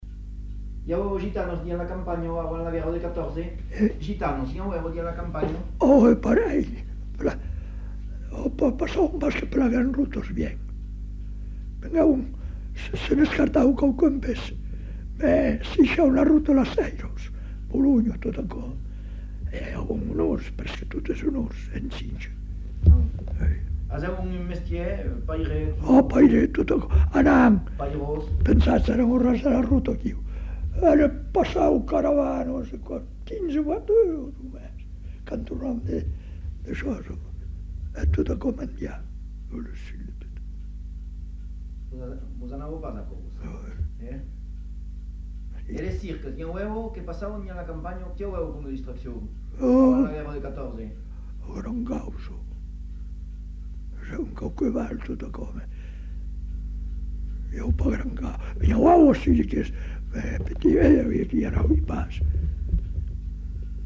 Lieu : Monblanc
Genre : témoignage thématique